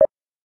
menu-exit-click.wav